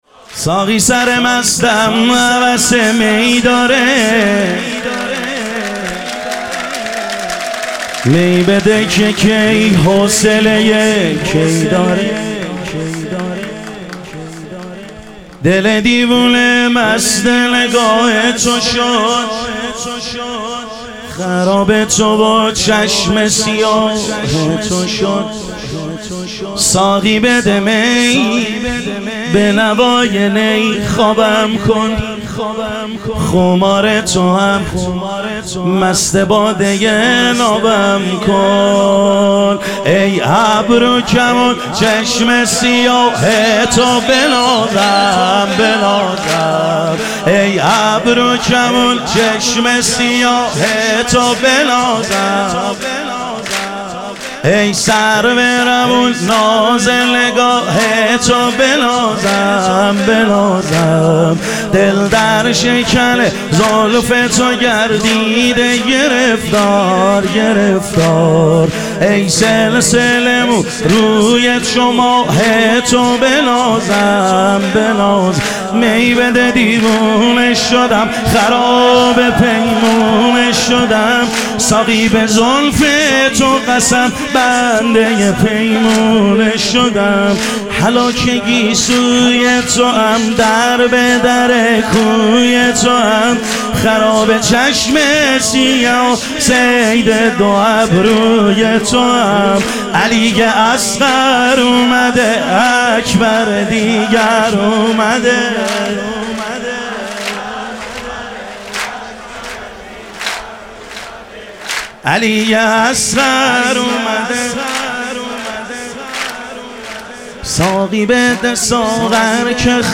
شب ظهور وجود مقدس امام جواد و حضرت علی اصغر علیهم السلام